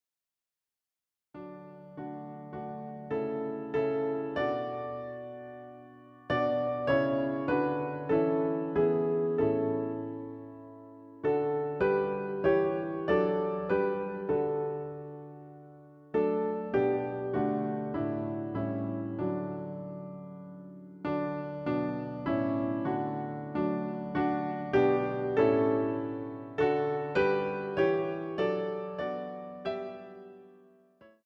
CD quality digital audio Mp3 file recorded
using the stereo sampled sound of a Yamaha Grand Piano.